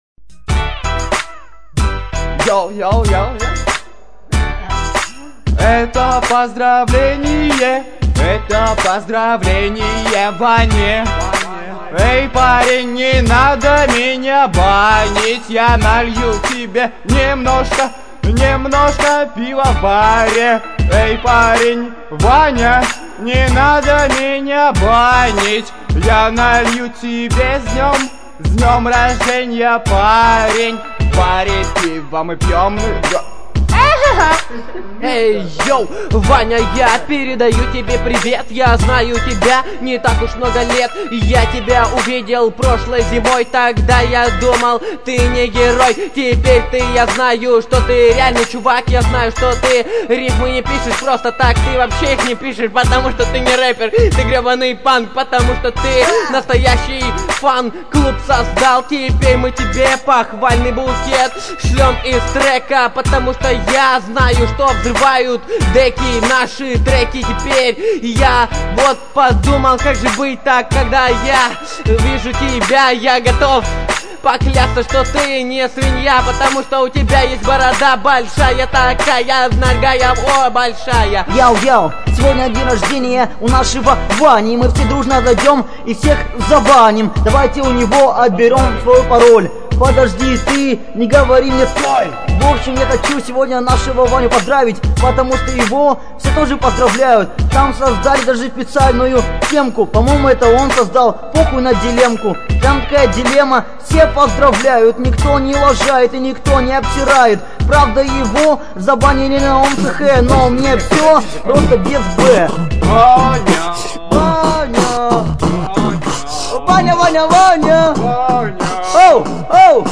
• Остальное:, 2007 Рэп